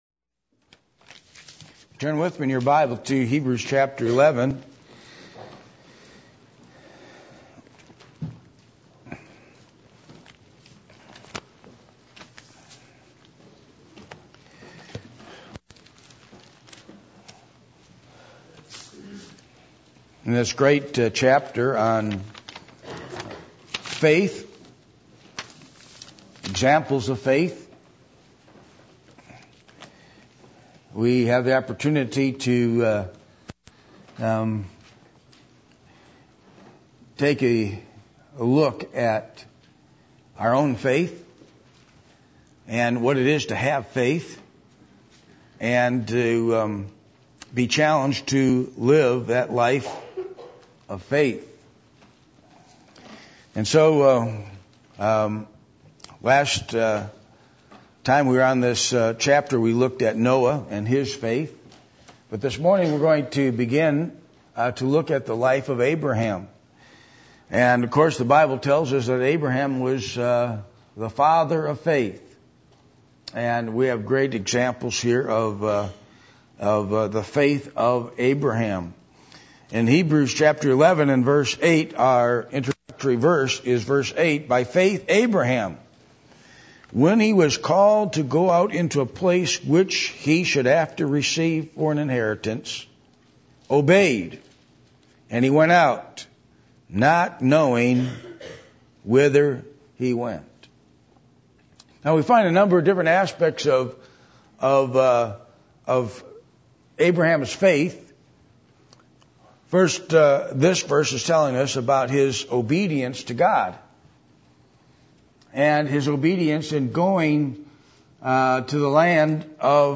Passage: Hebrews 11:18-19 Service Type: Sunday Morning %todo_render% « What Are Your Thoughts Toward God What Is Your Real Opinion Of The Messiah?